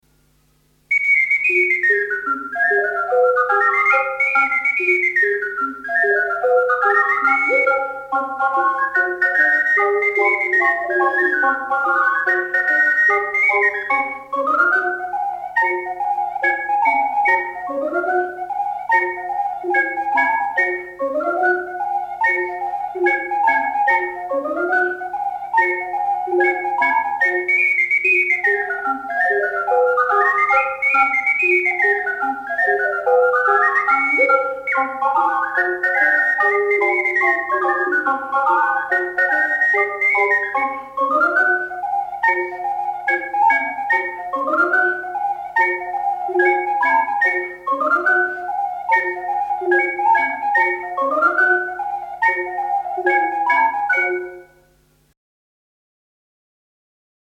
Balli popolari emiliani in .mp3
in incisione multipla con 5 ocarine